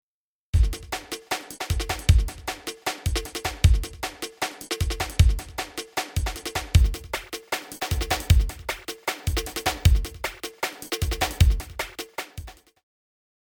パンパン
音を左右に振り分けること。